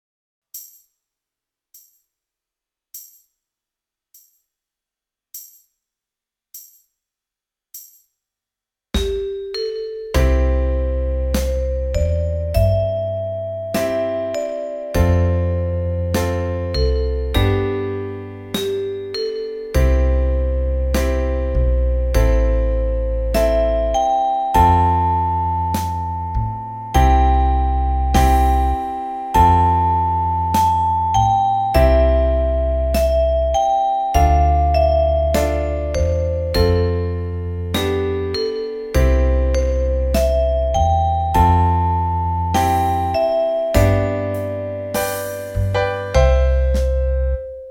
eBook Liederbuch für die chromatische Mundharmonika.
Soundbeispiel – Melodie & Band sowie Band alleine: